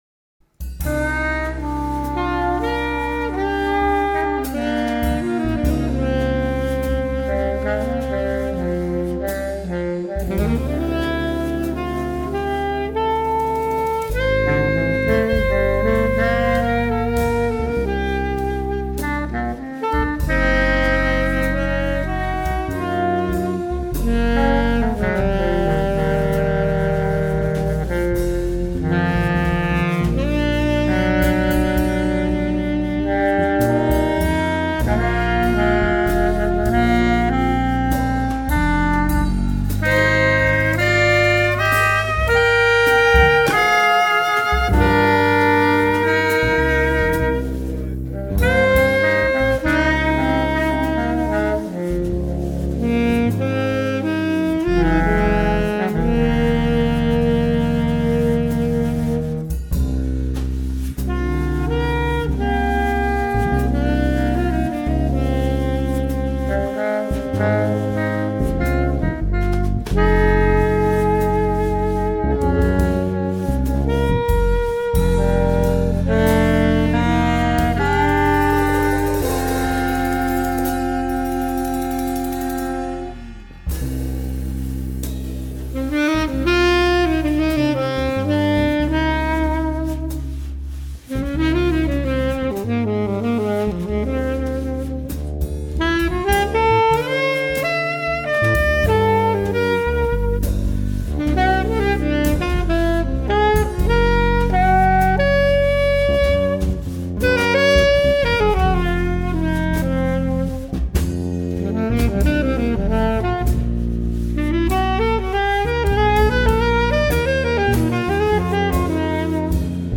Altsax
Baritonsax
Fretless E-Bass
Drums